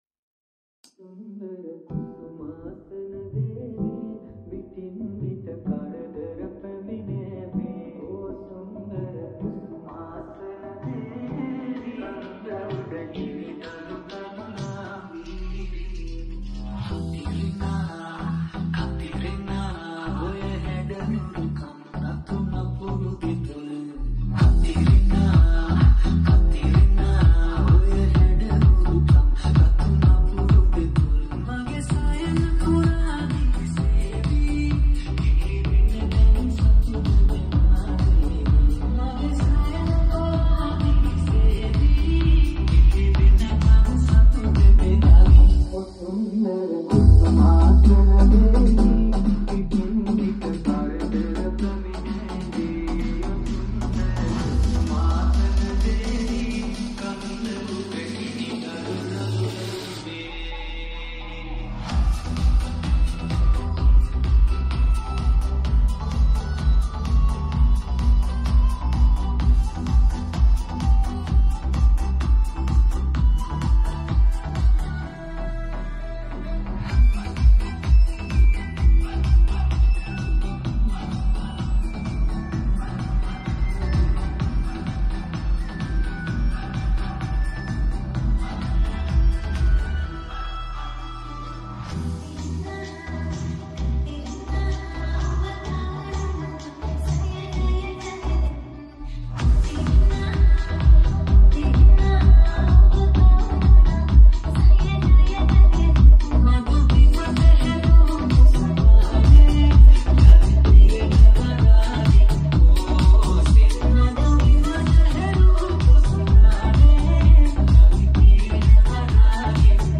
Mass Remix